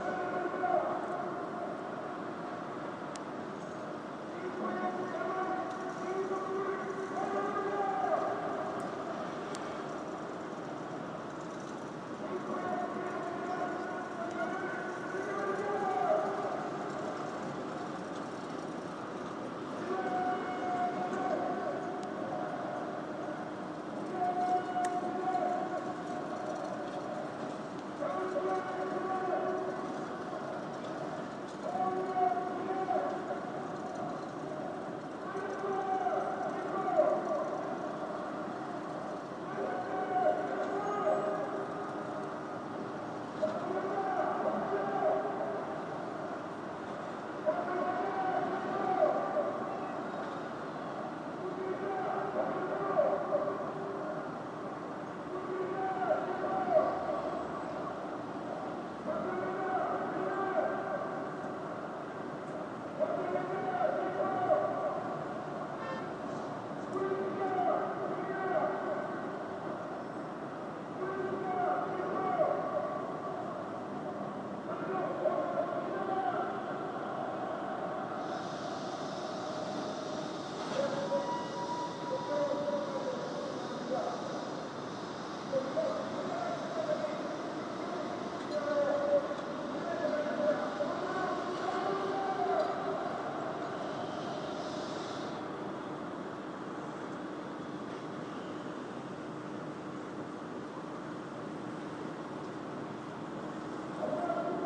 Ambient sounds of the Seoul street_ protests and traffic in Mapo
Ambient-sounds-of-the-Seoul-street_-protests-and-traffic-in-Mapo.mp3